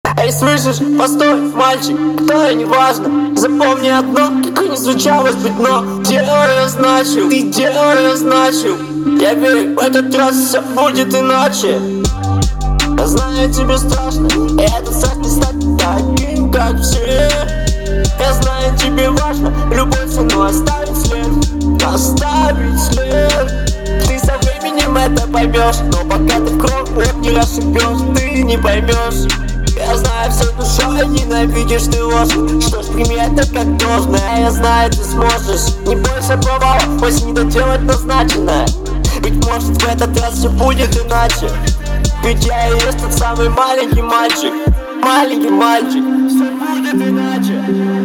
Текст более-менее сносный, но перегруз эффектов мешает его воспринимать.